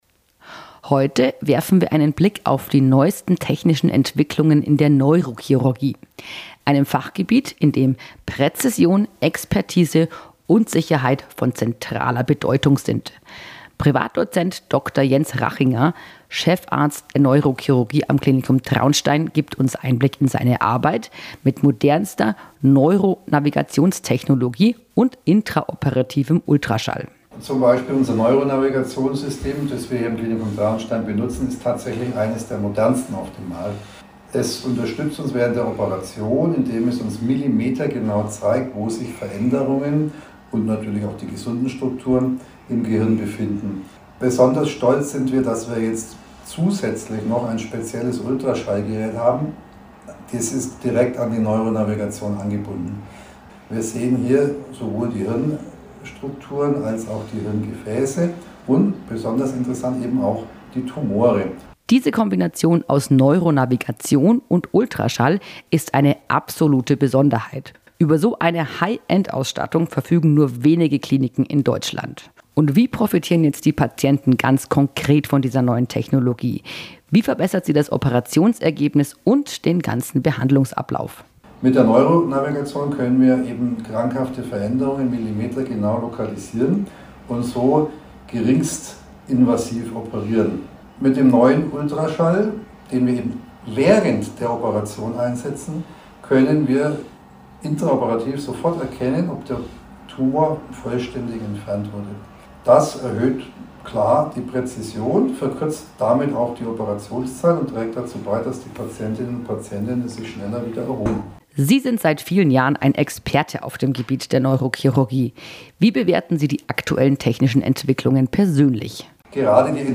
Im Format „Gesundheit KOMPAKT“ mit der Bayernwelle Südost sprechen Mediziner, Therapeuten und Pflegekräfte über medizinische Themen oder Aktuelles aus den Kliniken Südostbayern AG.